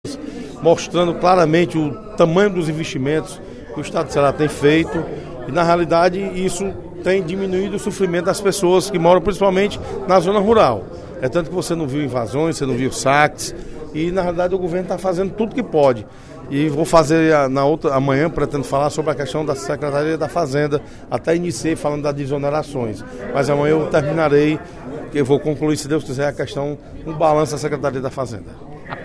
Durante o primeiro expediente da sessão plenária desta quinta-feira (11/12), o deputado Osmar Baquit (PSD) fez um balando das ações realizadas pelo governador Cid Gomes (Pros) e pelo secretário de Recursos Hídricos do estado, Francisco Rennys Aguiar Frota.